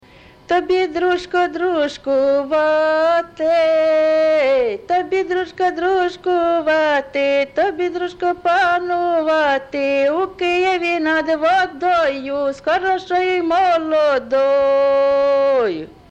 ЖанрВесільні
Місце записус. Яблунівка, Костянтинівський (Краматорський) район, Донецька обл., Україна, Слобожанщина